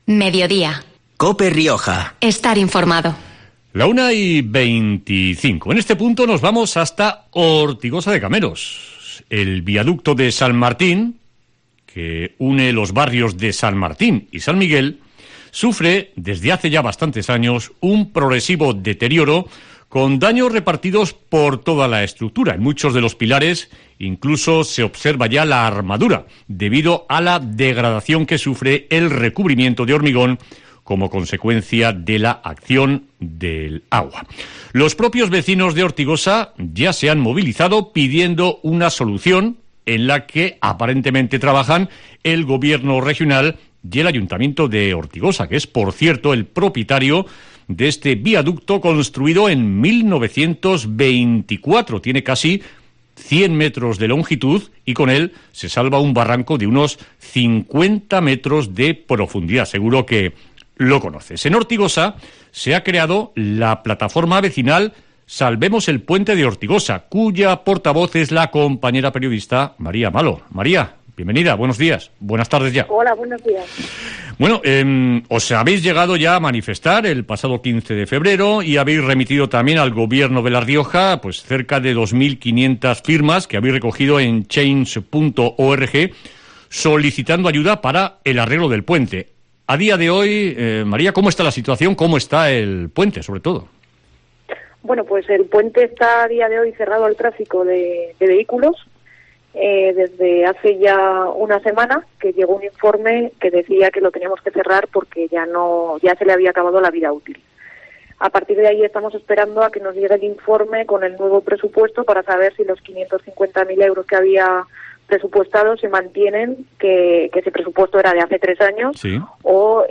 Entrevista en COPE Rioja